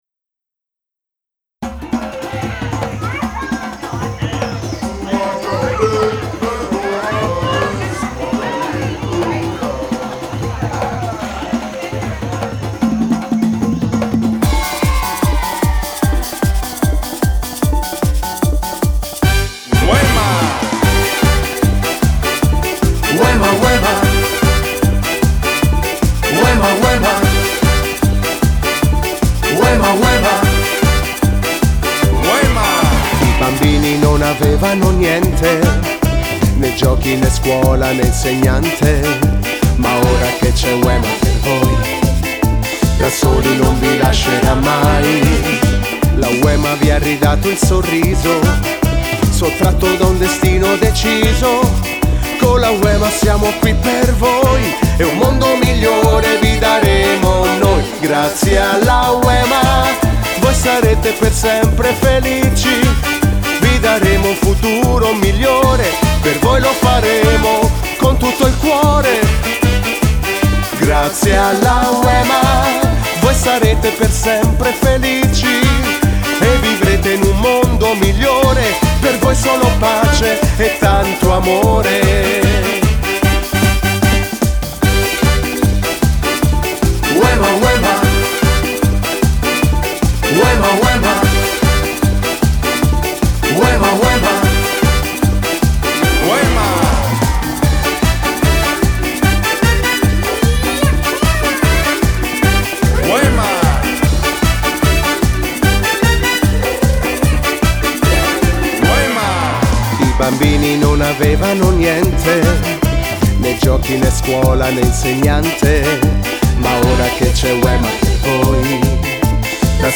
Merengue (Ballo di gruppo)